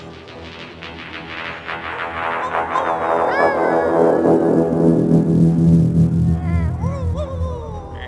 Today they are working on a techno-trance track.
The kick and the bass have a rather "deep"
created to be played in loops.